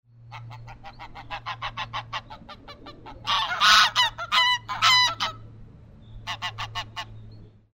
Greylag Goose  Anser anser
Sonogram of Greylag Goose calls
Walthamstow Reservoirs, London, England  51° 34' 47.1'' N  00° 03' 30.4'' W  10 May 2009
Agitation and alarm calls.